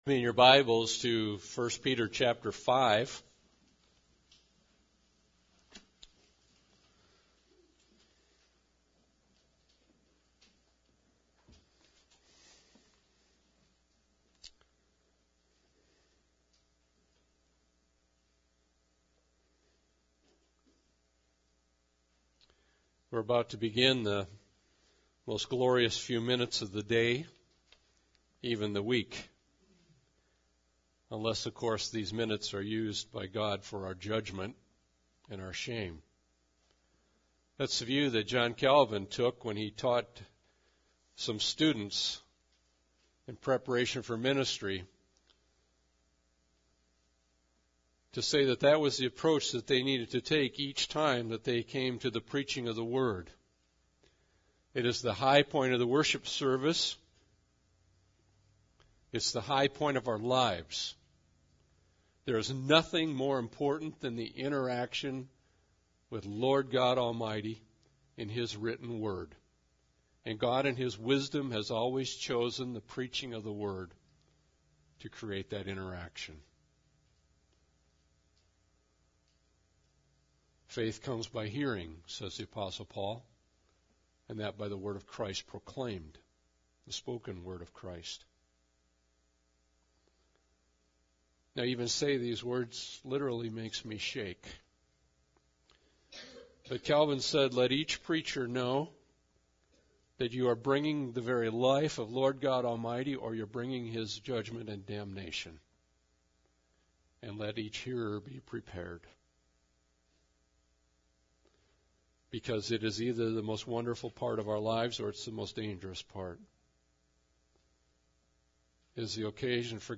Passage: 1 Peter 5 Service Type: Sunday Service